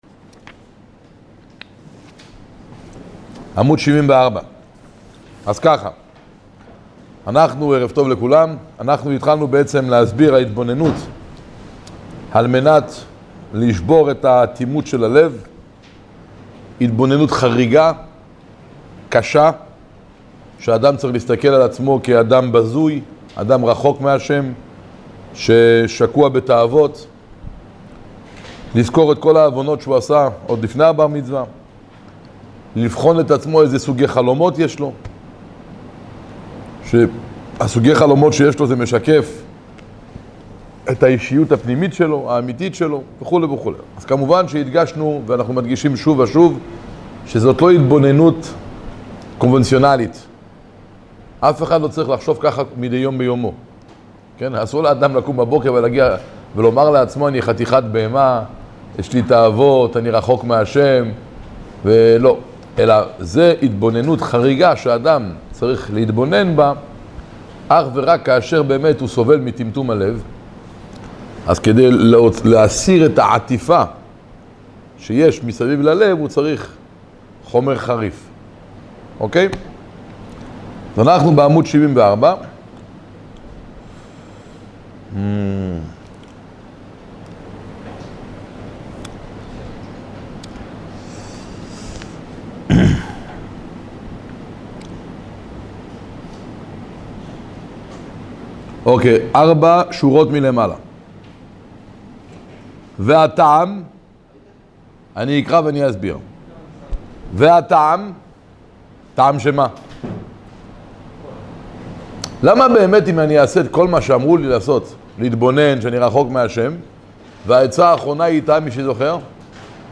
כיצד מתייחסים למכשולים? ● שיעור תניא